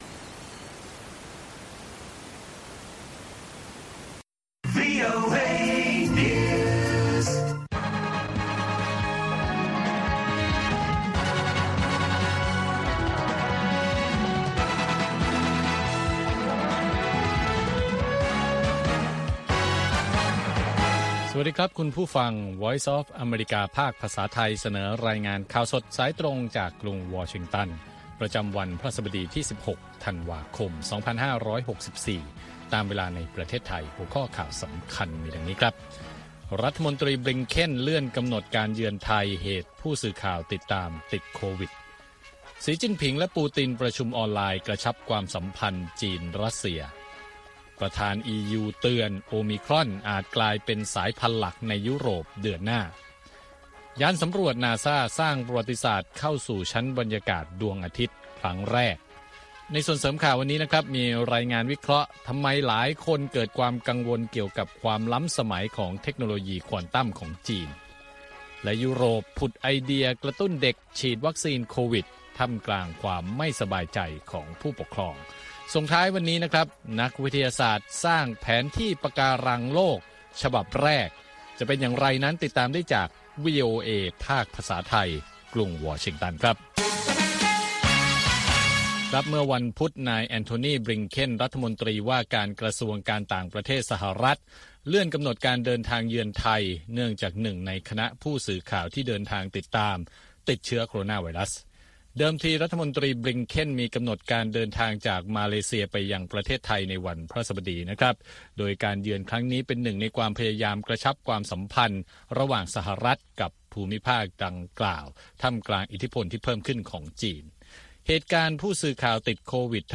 ข่าวสดสายตรงจากวีโอเอ ภาคภาษาไทย 6:30 – 7:00 น. ประจำวันพฤหัสบดีที่ 16 ธันวาคม2564 ตามเวลาในประเทศไทย